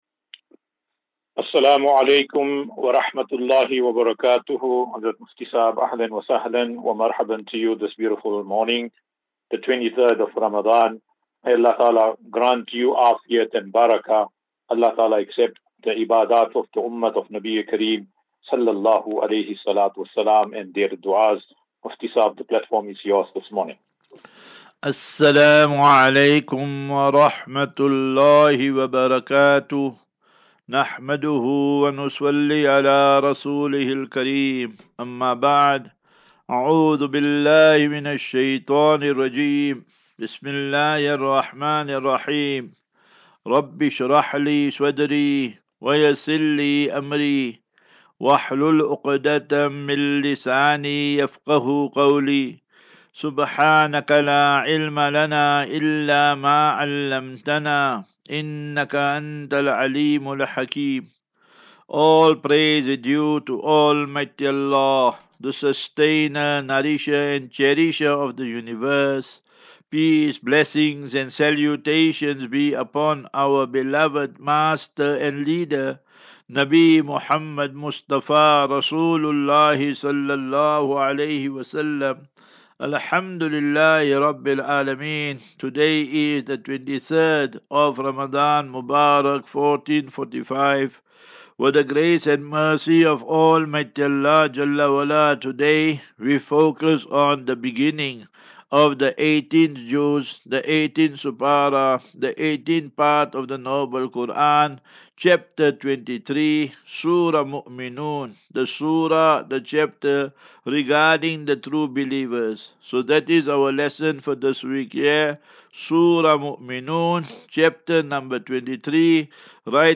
As Safinatu Ilal Jannah Naseeha and Q and A 3 Apr 03 April 2024.